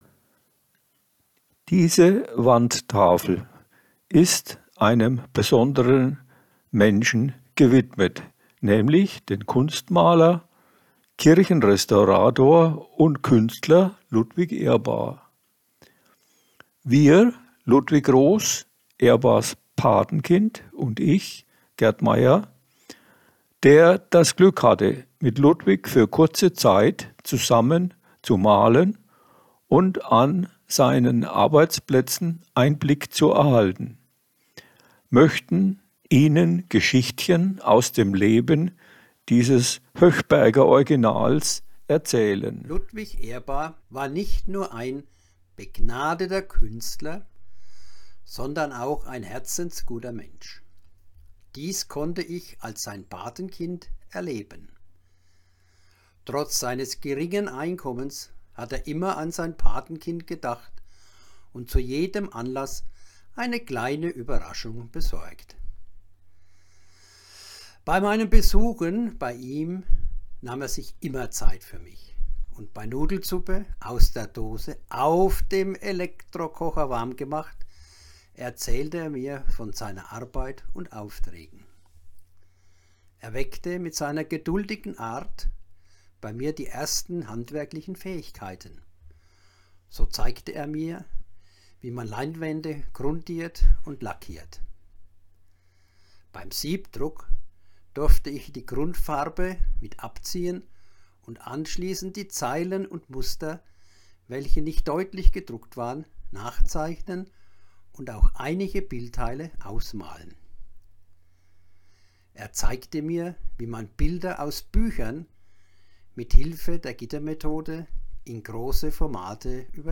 Audioführer